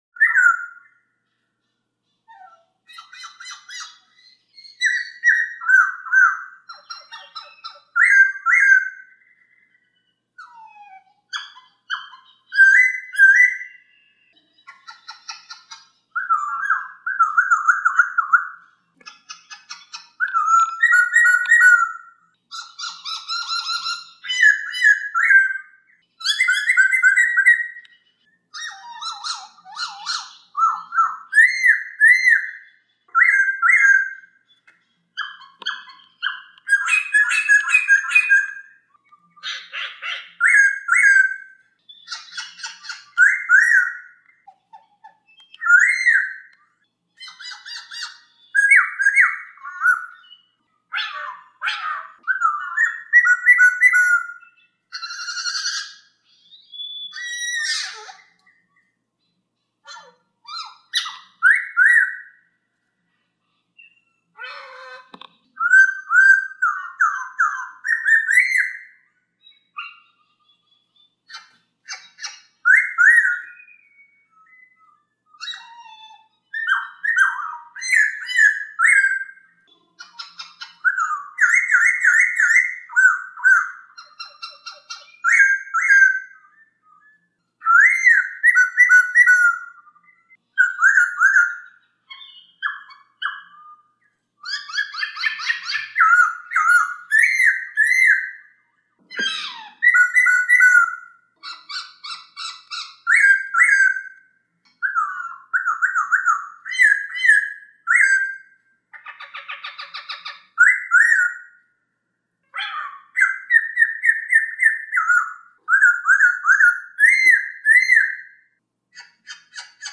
Suara Burung Poksay